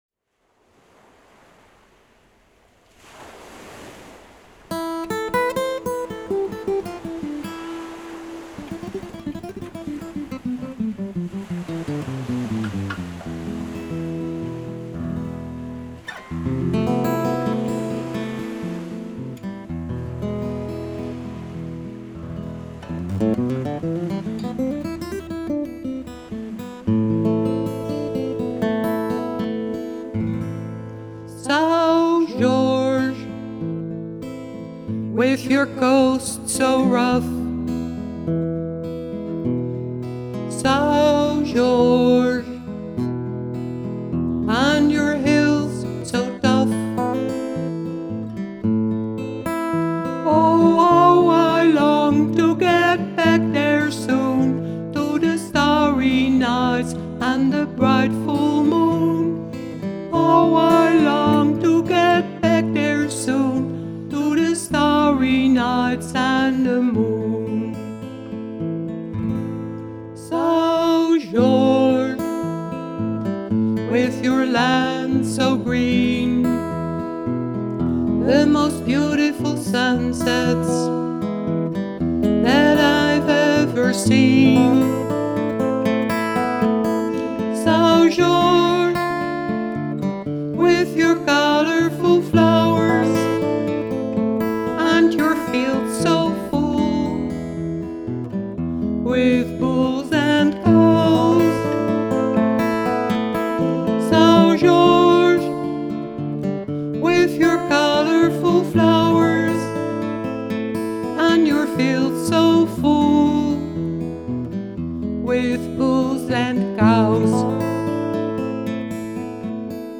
vocals
guitar
drums except on Scillies
Recorded in Studio PH14 ASE on Texel.